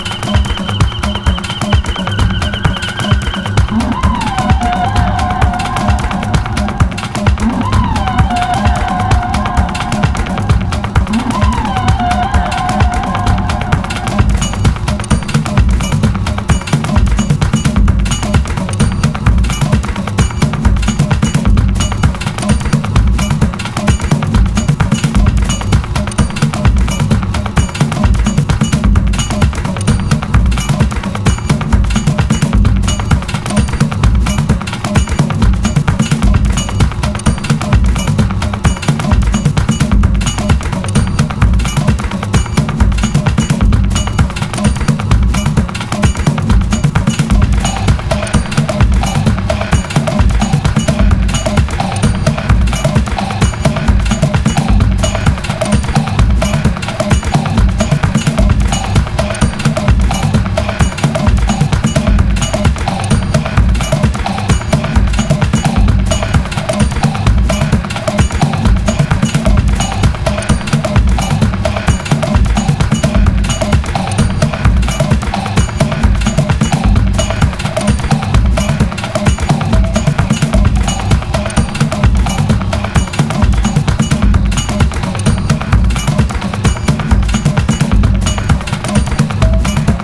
オーガニックなトライバル・ドラムの応酬